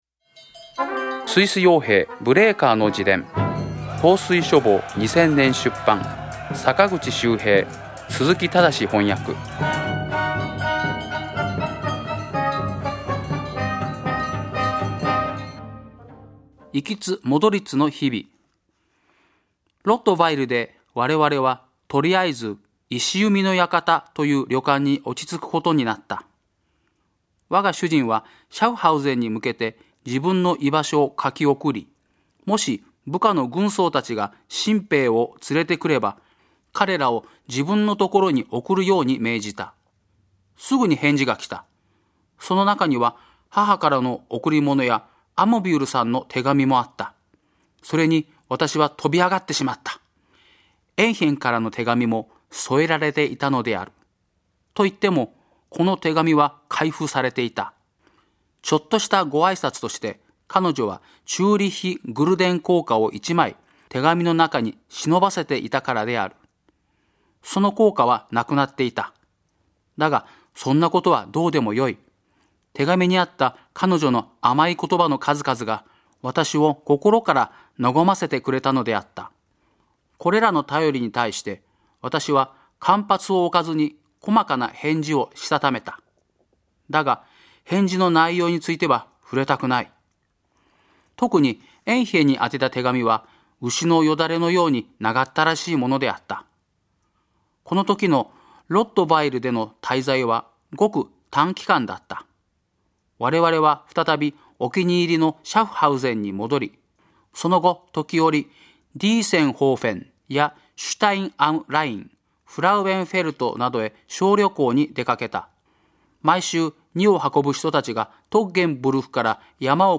朗読『スイス傭兵ブレーカーの自伝』第42回